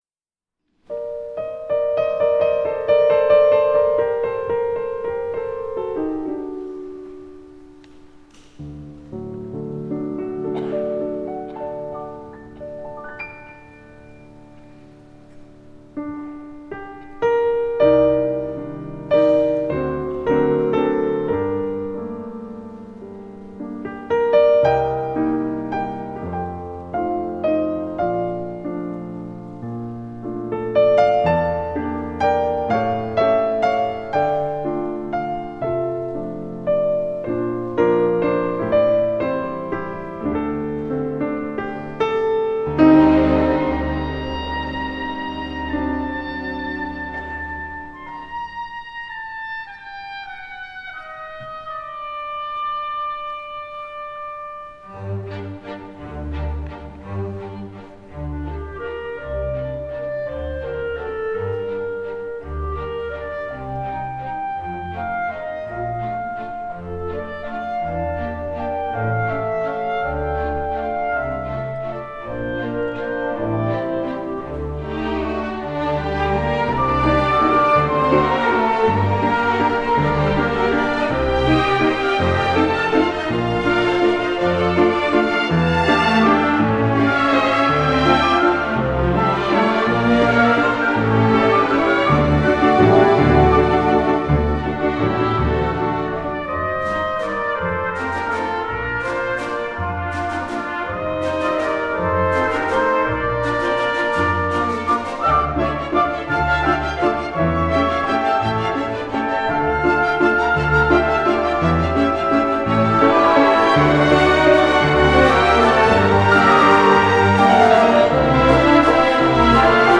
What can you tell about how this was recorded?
(Live) (Remastered)